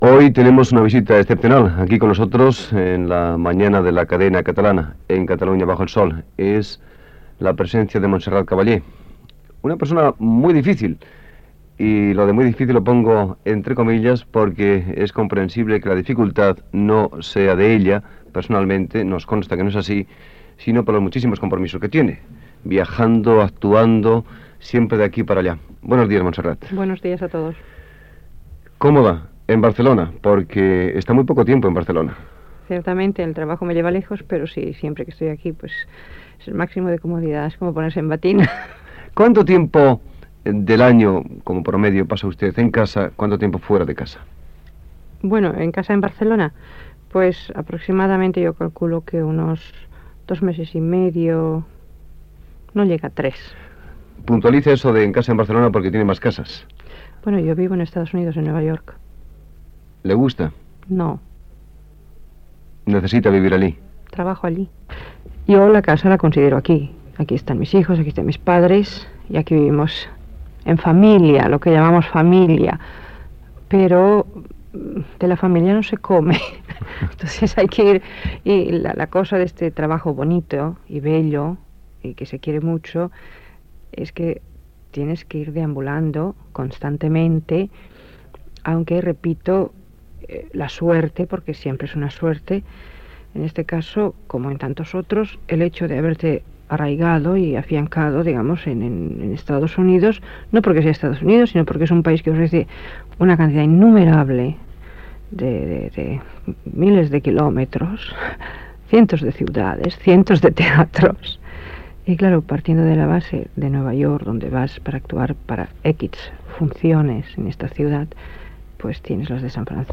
Radio España de Barcelona
Entrevista a la soprano Montserrat Caballé, quan tenia 47 anys. Sobre els seus viatges, vida artística i cura de la veu.